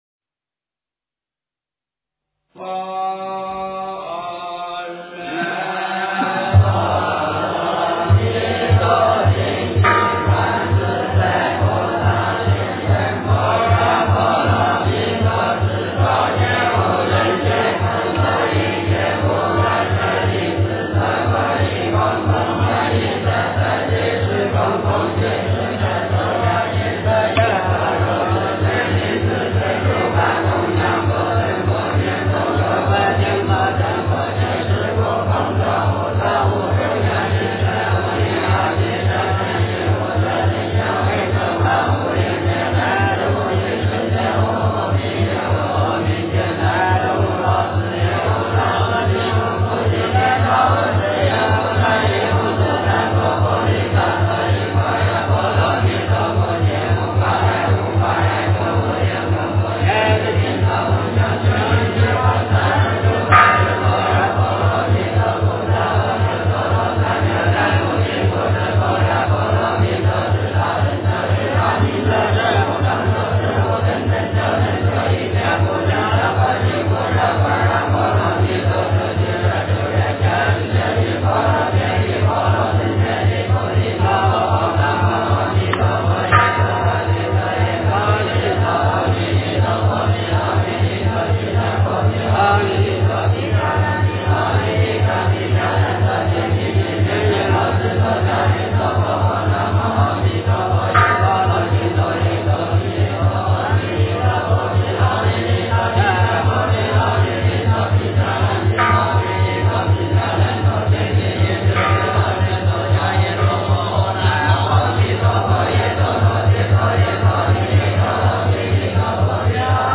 普佛(代晚课)(下)--僧团 经忏 普佛(代晚课)(下)--僧团 点我： 标签: 佛音 经忏 佛教音乐 返回列表 上一篇： 普佛(代晚课)(上)--僧团 下一篇： 三时系念--悟道法师 相关文章 《妙法莲华经》分别功德品第十七--佚名 《妙法莲华经》分别功德品第十七--佚名...